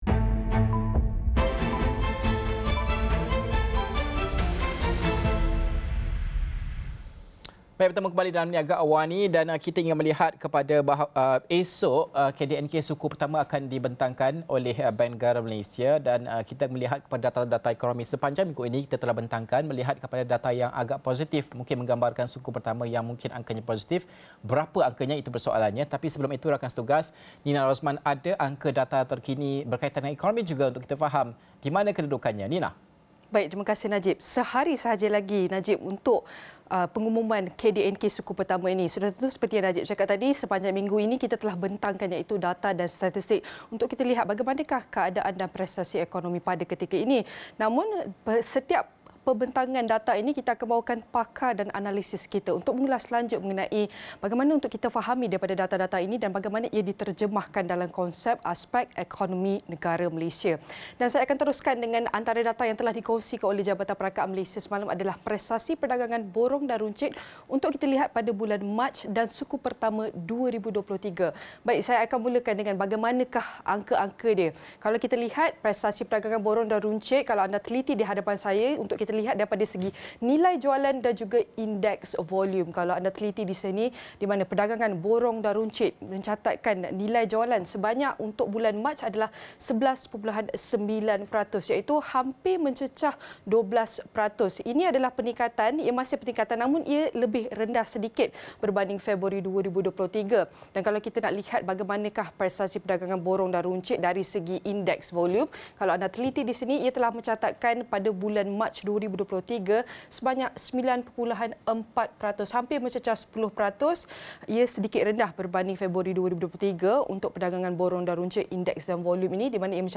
Headliner Embed Embed code See more options Share Facebook X Subscribe Apakah jangkaan pertumbuhan Keluaran Dalam Negara Kasar (KDNK) bagi suku pertama 2023? Bersama Pakar Ekonomi